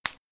CZ20 bubble wrap